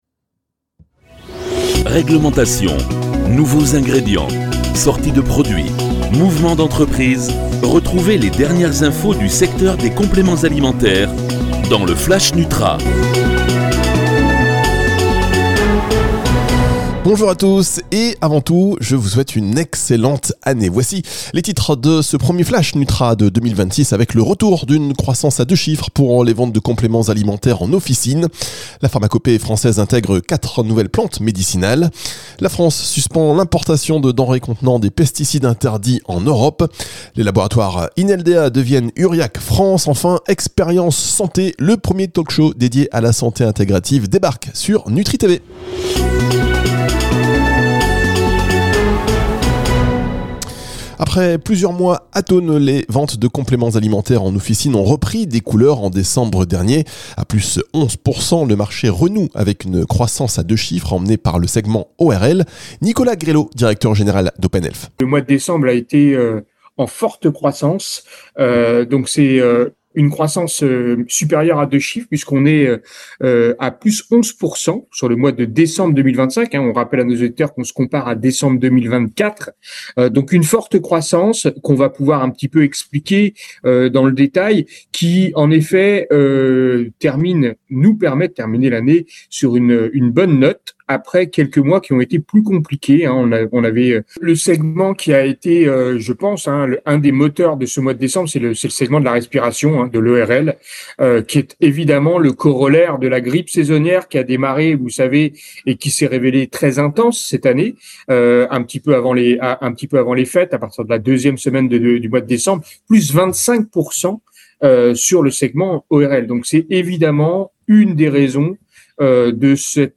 Infos réglementaires, nouveaux ingrédients, lancement de produits, innovation, mouvements d'entreprises, politique RSE, recrutement...Toutes les news de la nutraceutique condensées dans un flash audio.